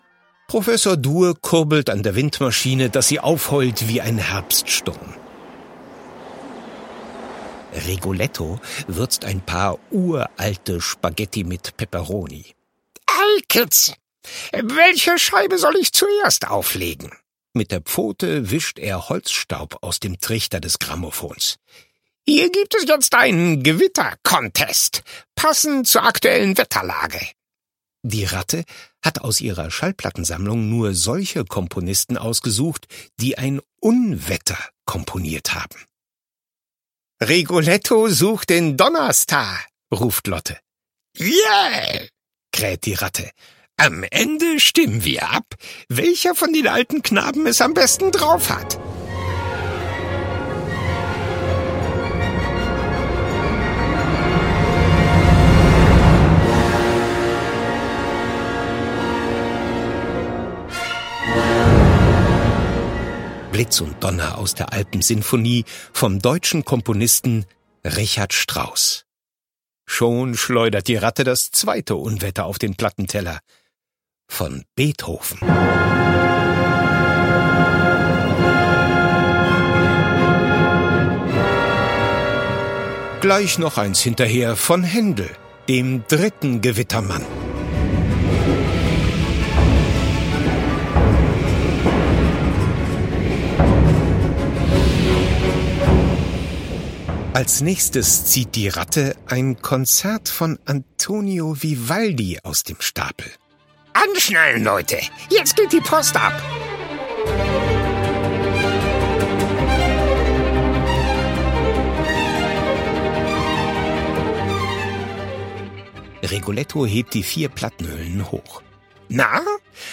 Schlagworte Antonia Vivaldi • Detektivgeschichte • Die vier Jahreszeiten (Vivaldi); Kindersachbuch/Jugendsachbuch • Hörbuch; Lesung für Kinder/Jugendliche • Klassik für Kinder • Klassische Musik • Vivaldi, Antonio; Kindersachbuch/Jugendsachbuch • Zeitreisegeschichte